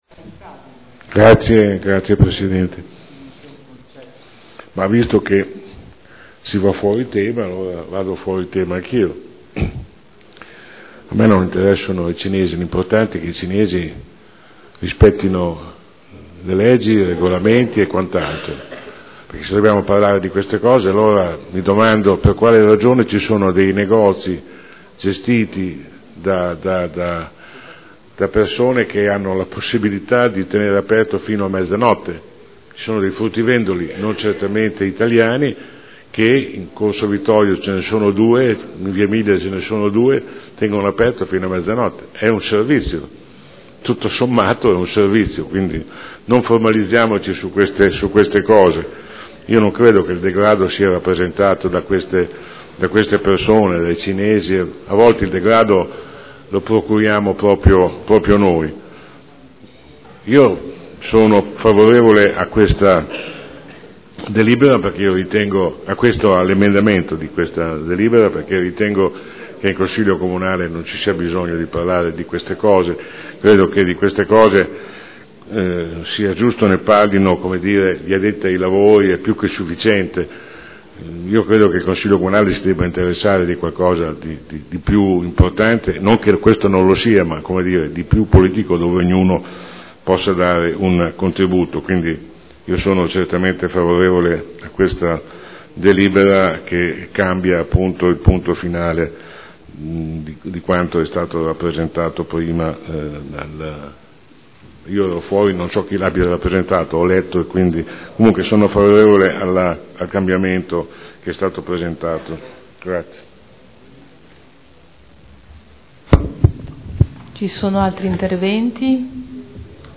Seduta del 12/12/2013 Proposta di deliberazione: Mercato settimanale del lunedì – Variazione posteggi . Dibattito